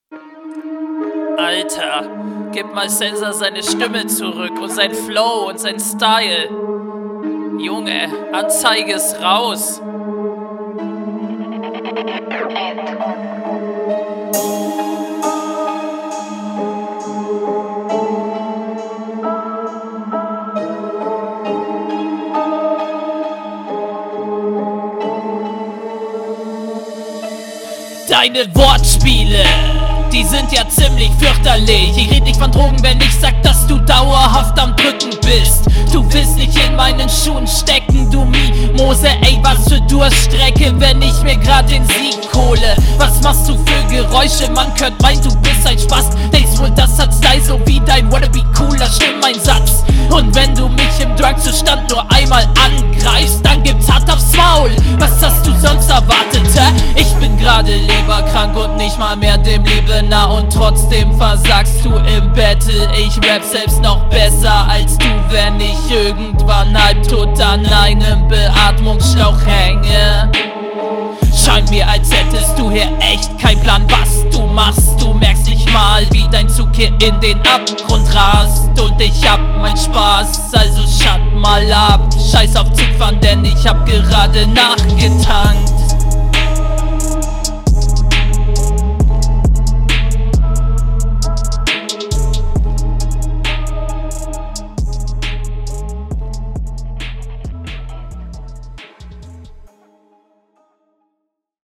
Dein Stimmeinsatz, deine Mische, deine Punches und deine Konter dominieren die Runde.